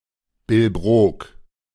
German pronunciation) is a quarter of Hamburg, Germany, in the borough of Hamburg-Mitte.
De-Billbrook.ogg.mp3